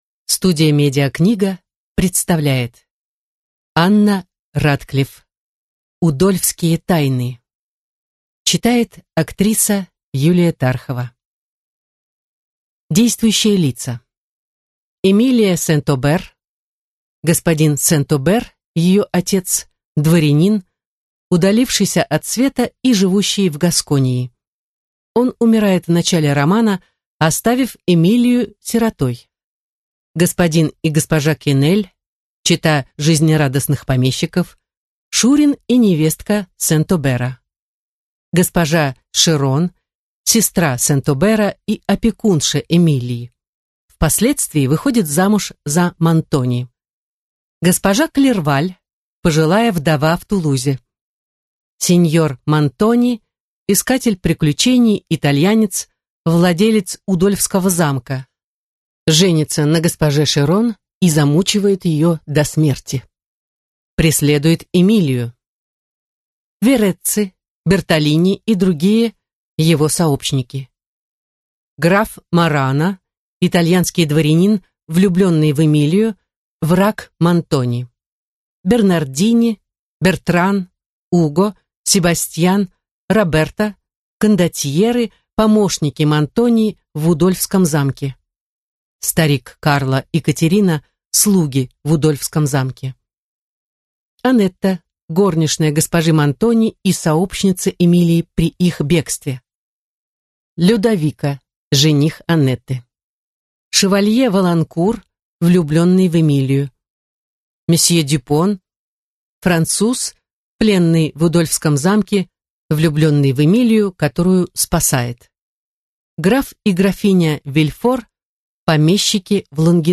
Аудиокнига Удольфские тайны | Библиотека аудиокниг